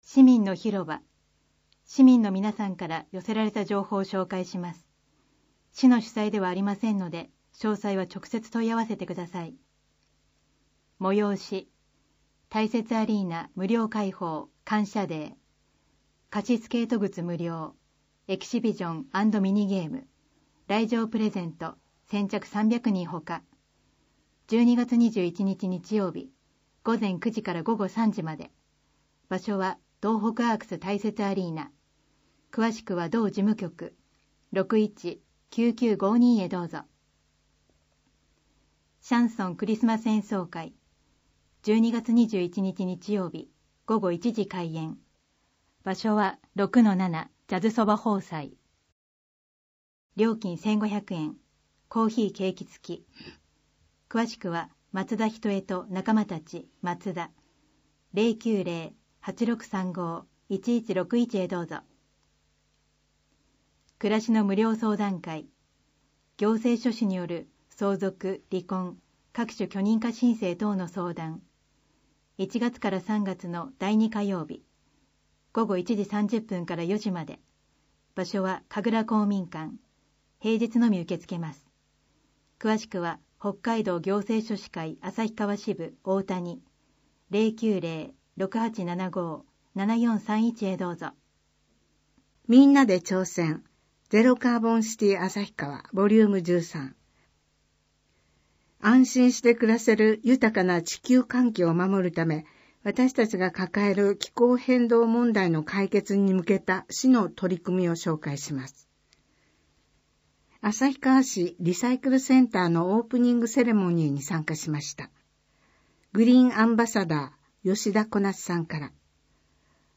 広報誌の内容を要約し、音訳した声の広報「あさひばし」を、デイジー図書版で毎月発行しています。